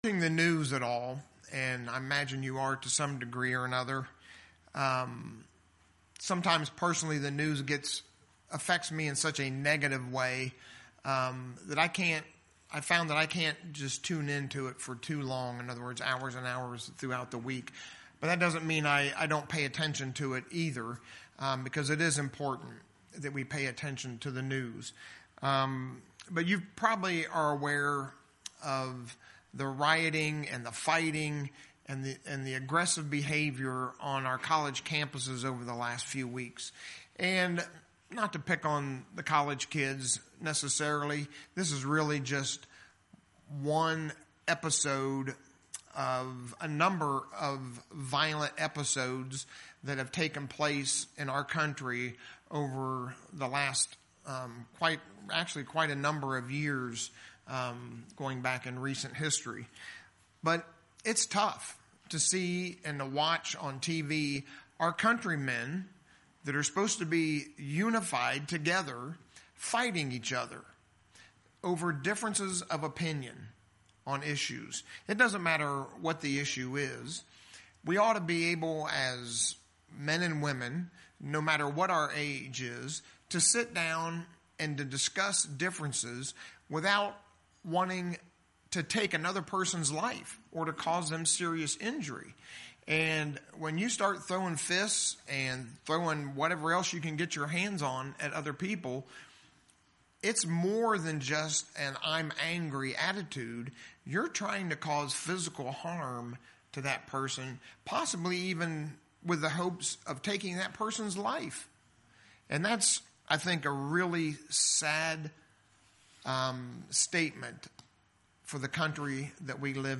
In fact, as we shall see, He went out of His way to avoid aggression. In our sermon today we'll see the importance of living a harmless life.